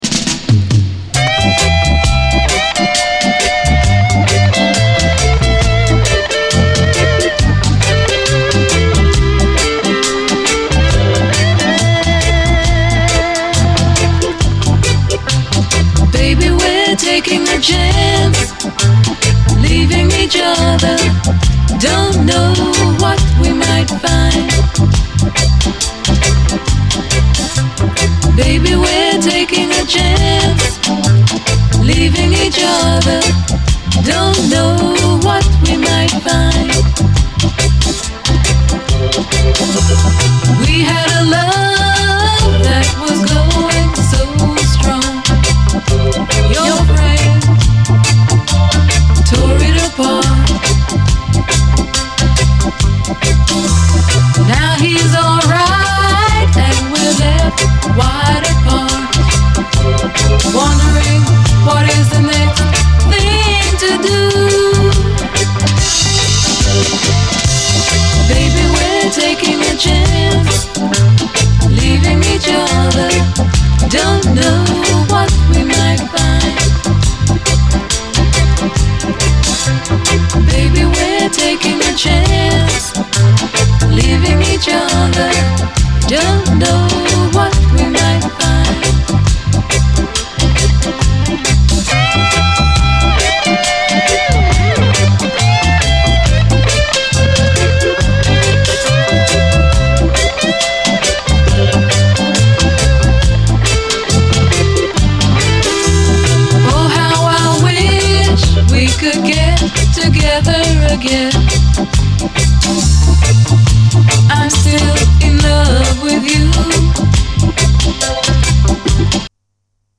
REGGAE
ギラついたギターが切なく響く哀愁スウィート・レゲエ
UKラヴァーズ黎明期の女性シンガー
フワフワしたヴォーカルの処理など全体として確実に後のUKラヴァーズへと繋がる甘酸っぱいサウンドを実感できます。